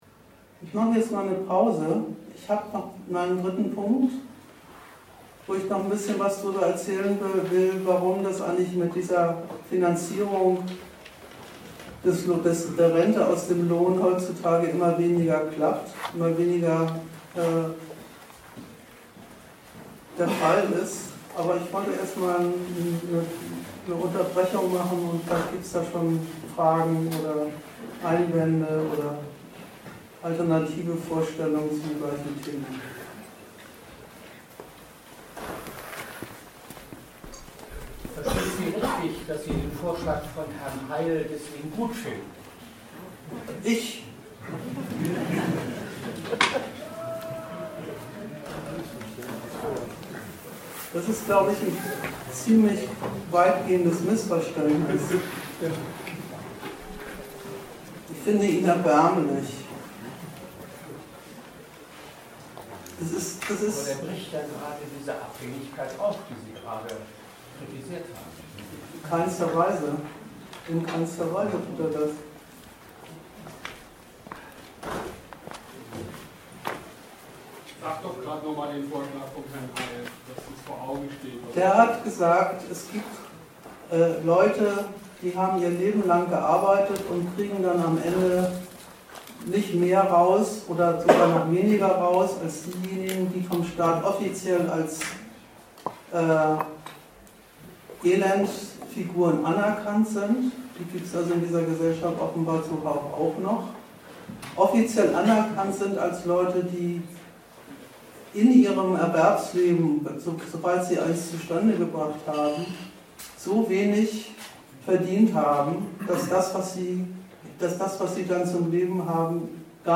Datum 16.05.2019 Ort Bremen Themenbereich Arbeit, Kapital und Sozialstaat Veranstalter Argudiss Dozent Gastreferenten der Zeitschrift GegenStandpunkt Sozialversicherungspflichtig beschäftigt?